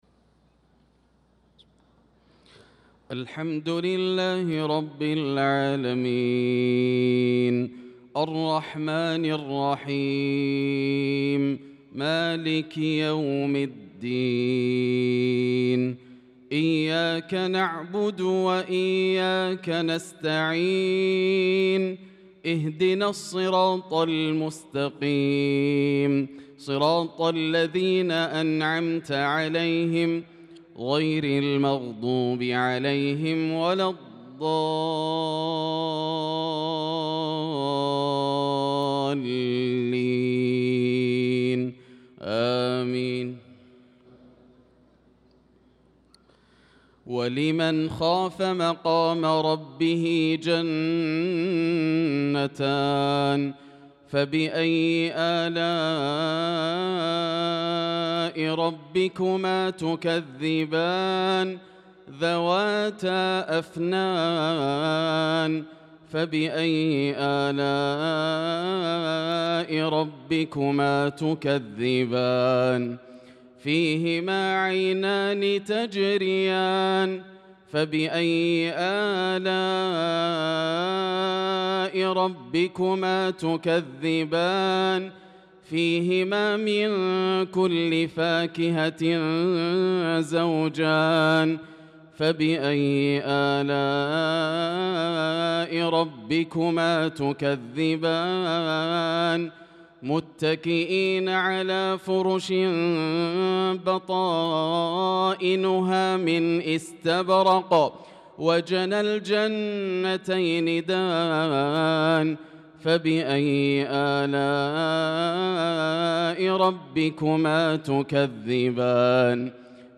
صلاة العشاء للقارئ ياسر الدوسري 10 شوال 1445 هـ
تِلَاوَات الْحَرَمَيْن .